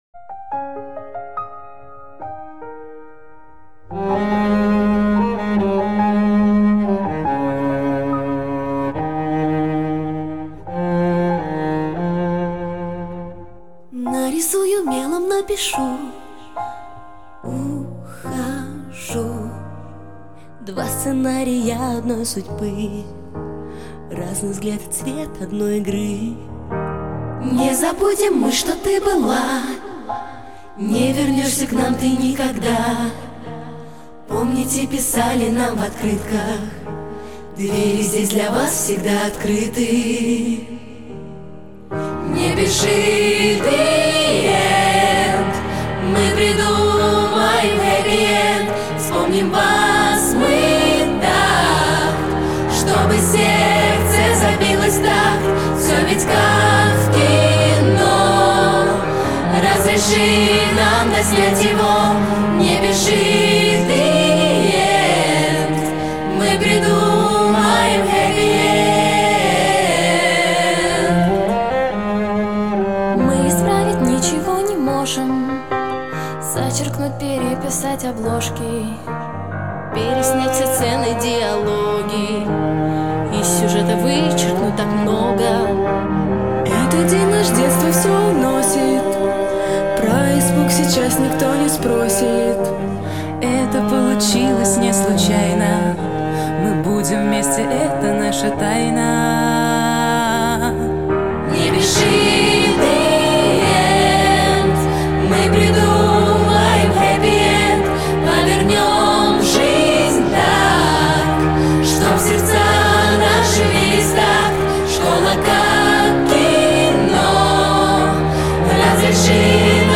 • Категория: Детские песни
теги: выпускной, песни переделки, пародия, минус